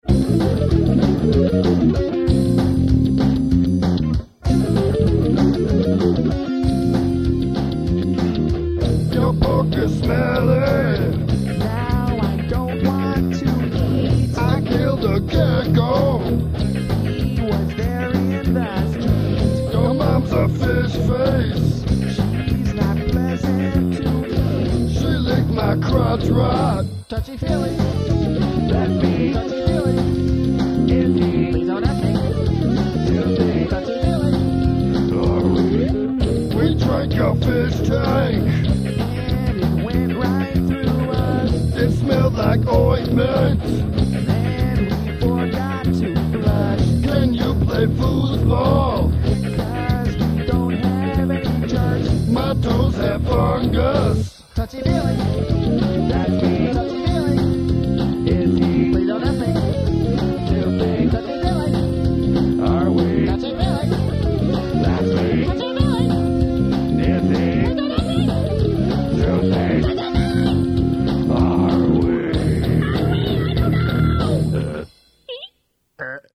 I have chosen a select few here that were recorded in the living room studio in "The Apartment" in the late '90s, around '98 or so.
These songs were written in extreme haste, recorded with more haste, with very little attention to detail, usually each instrument in one take only.
bass, shared vox